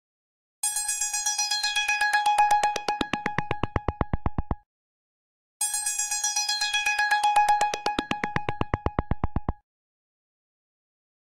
Catégorie Telephone